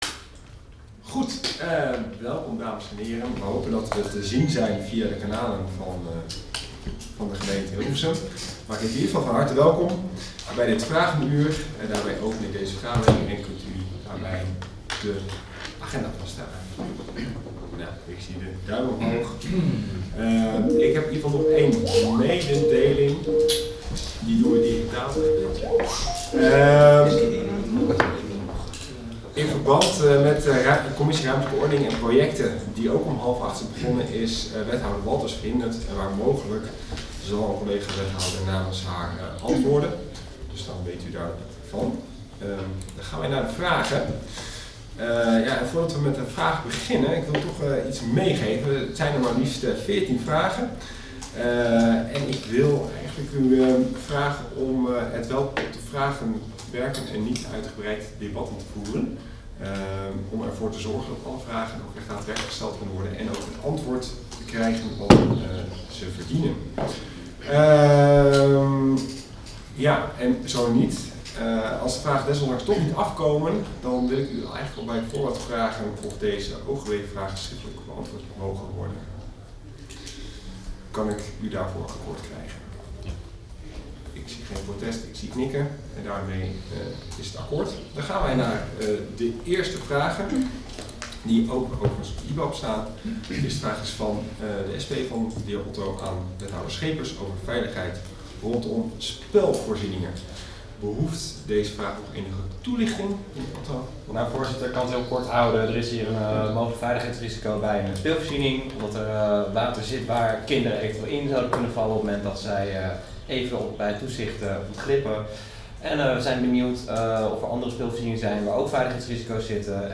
Agenda Hilversum - Commissies - Vragenuur woensdag 28 juni 2023 19:30 - 20:30 - iBabs Publieksportaal